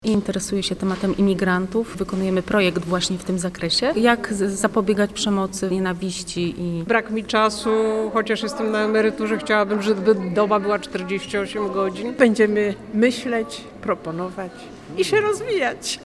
Posłuchaj wypowiedzi uczestników wydarzenia: